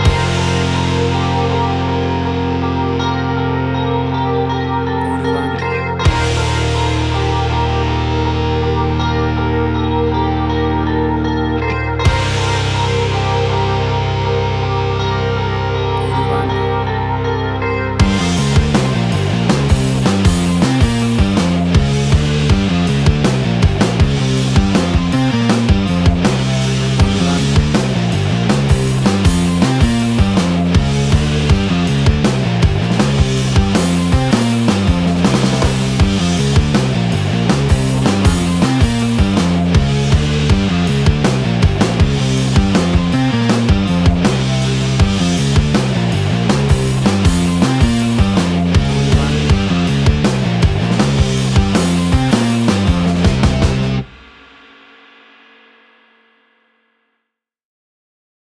Tempo (BPM): 80